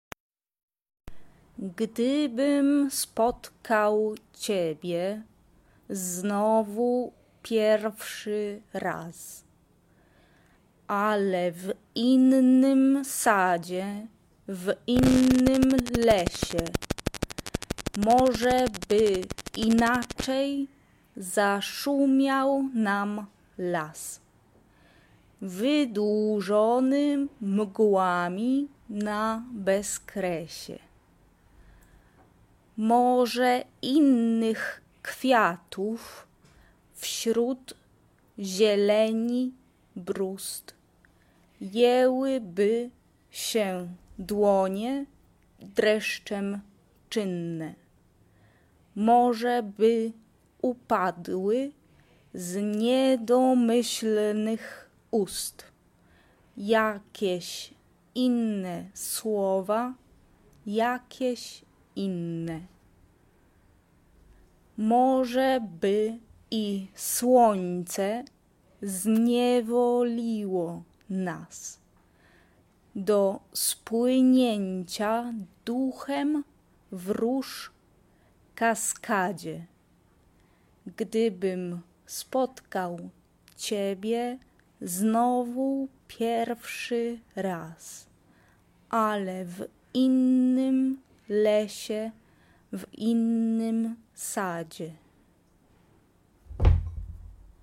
SSA (4 voces Coro femenino) ; Partitura general.
Instrumentación: arpa (1 partes instrumentales)
Tonalidad : tonal